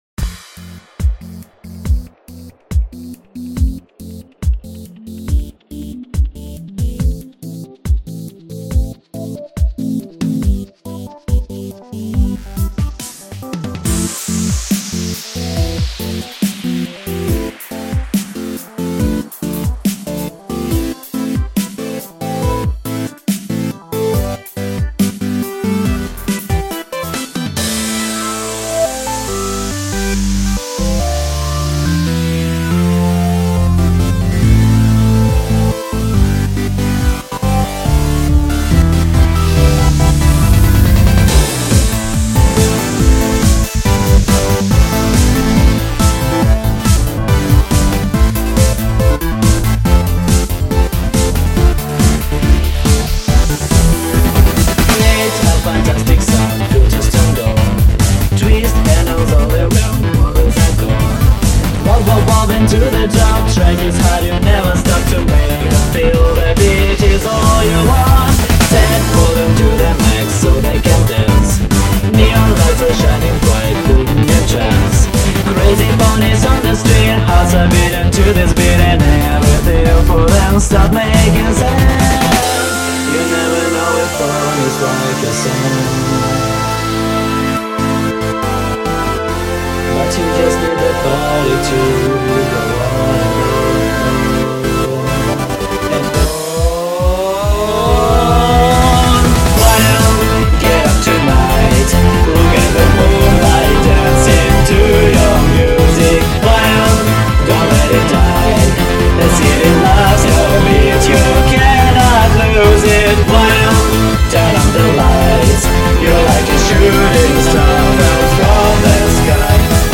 electronic pony duo from Saint Petersburg